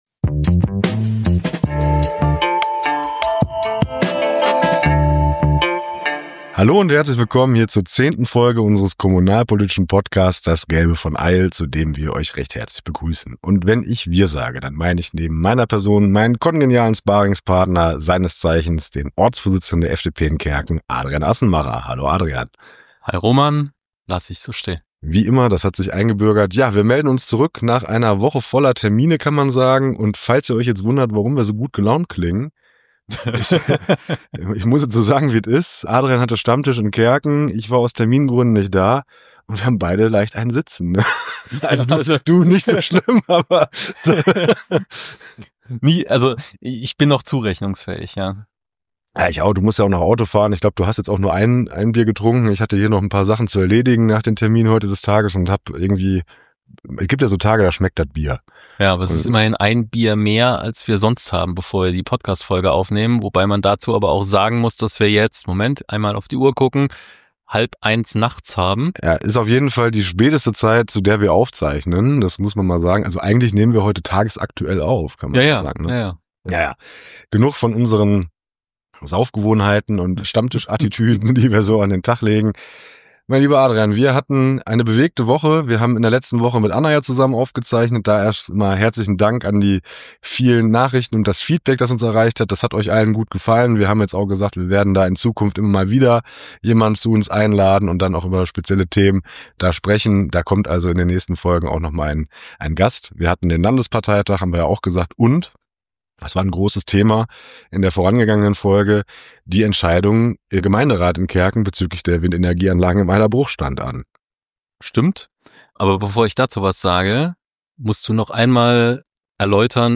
In dieser Folge ist alles dabei: Kommunalpolitik bei Dosenbier, nächtliche Podcast-Aufnahme, die große Debatte um die Windenergieanlagen im Eyller Bruch und ein Rückblick auf den Landesparteitag der FDP NRW!